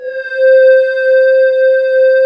Index of /90_sSampleCDs/USB Soundscan vol.28 - Choir Acoustic & Synth [AKAI] 1CD/Partition D/26-VOCOSYNES